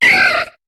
Cri de Rosélia dans Pokémon HOME.